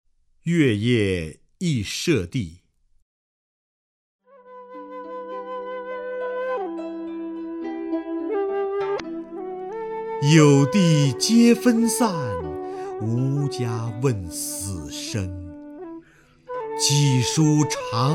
瞿弦和朗诵：《月夜忆舍弟》(（唐）杜甫)　/ （唐）杜甫
名家朗诵欣赏 瞿弦和 目录